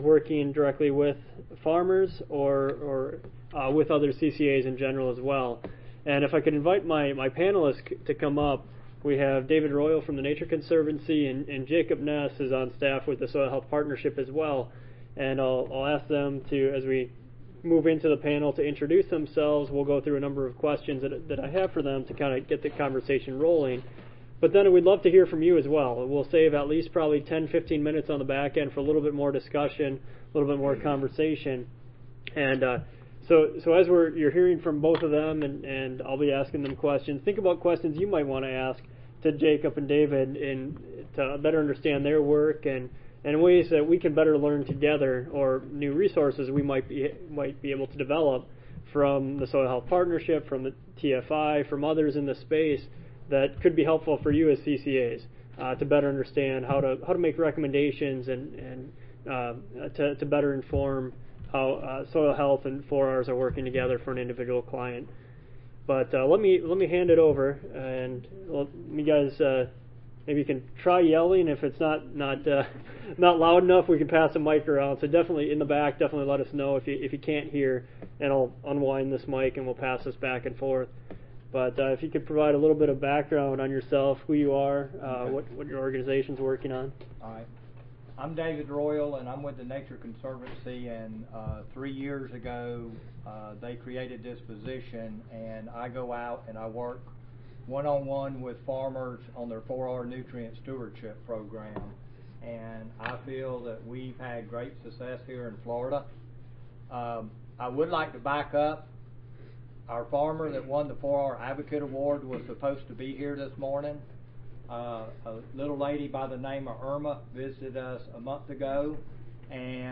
A panel of growers that are implement 4R practices and monitoring soil health will present the practices they are using, how they measure the success of a practice, how they measure and monitor soil health, and how they interpret soil health testing results.
The end of the session will be for questions and group discussion.